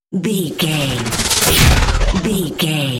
Whoosh to hit sci fi
Sound Effects
dark
futuristic
intense
tension